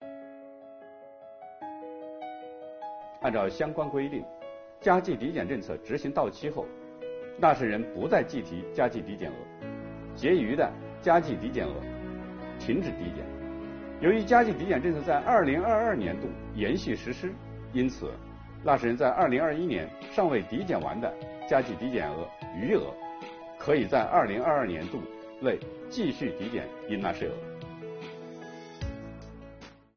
本期课程由国家税务总局货物和劳务税司副司长刘运毛担任主讲人，对2022年服务业领域困难行业纾困发展有关增值税政策进行详细讲解，方便广大纳税人更好地理解和享受政策。